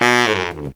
Index of /90_sSampleCDs/Best Service ProSamples vol.25 - Pop & Funk Brass [AKAI] 1CD/Partition C/BARITONE FX2